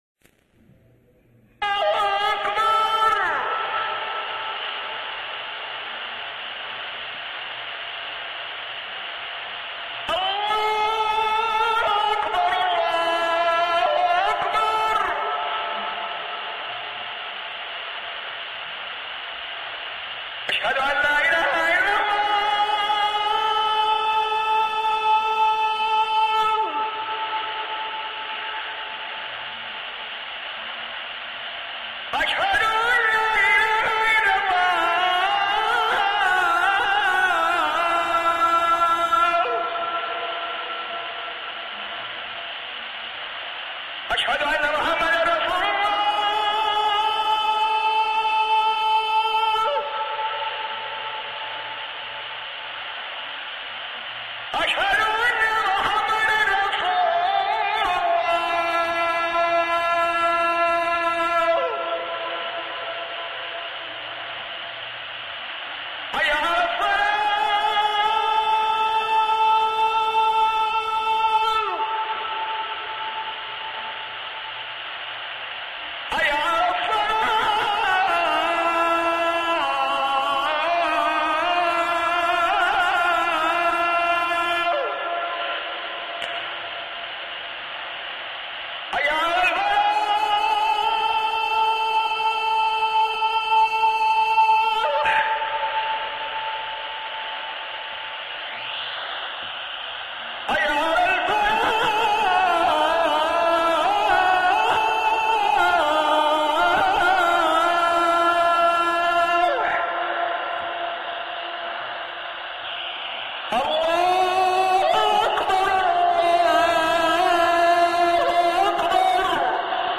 المكان: المسجد النبوي الشيخ
أذان